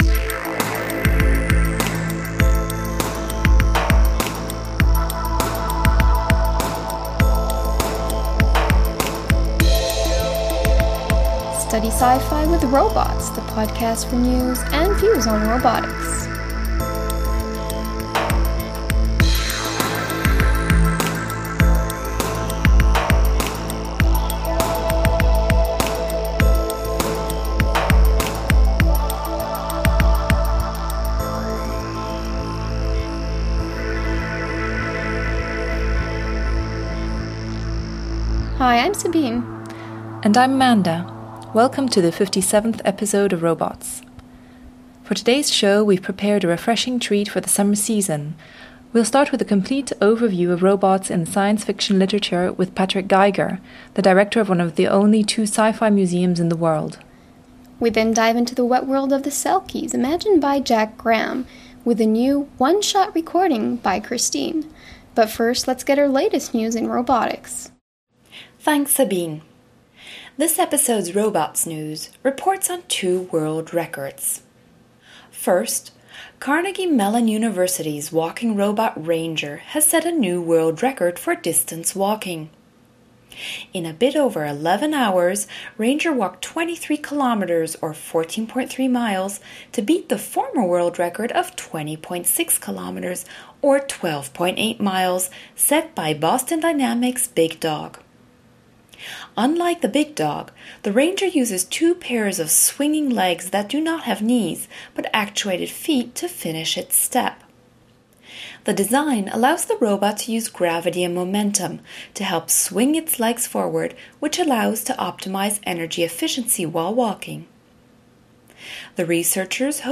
We’ve also added some nice sounds to allow you to immerse yourself into this world of waste, where seal-like robots strive to clean up the oceans.